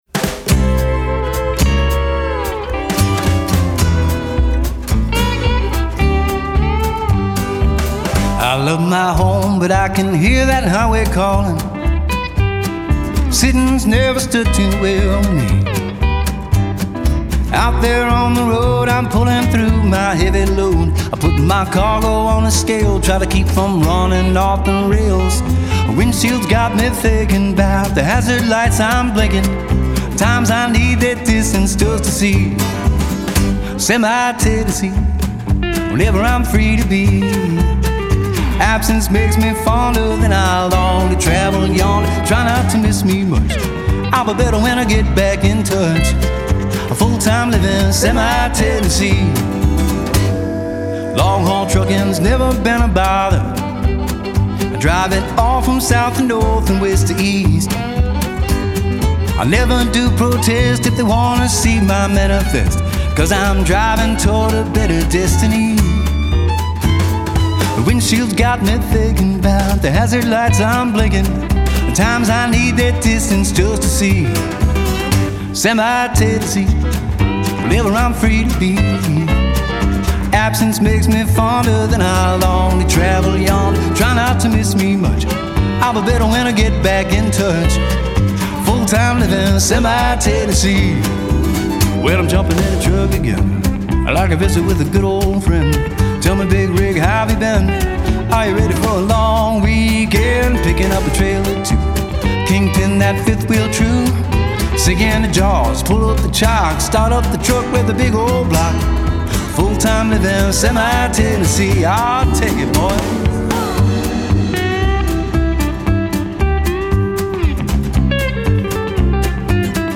"Semi-Tennessee" (country)